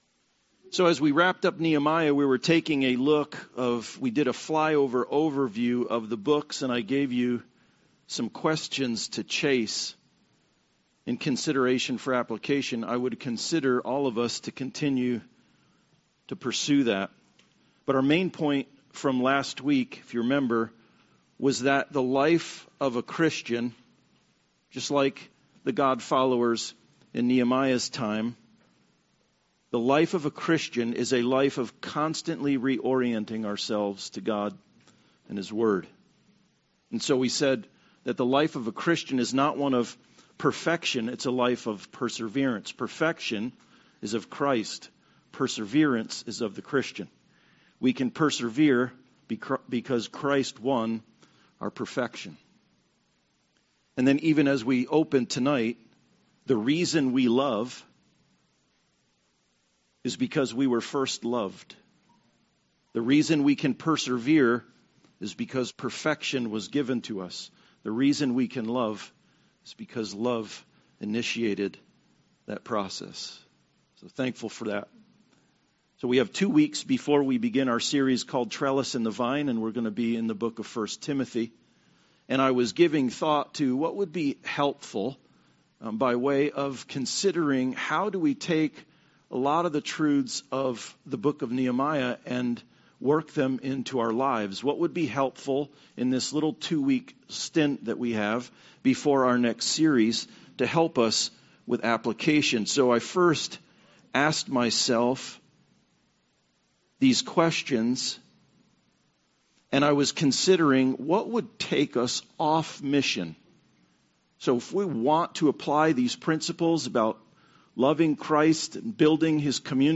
John 16:27 Service Type: Sunday Service God loves when we love Jesus and persevere in faith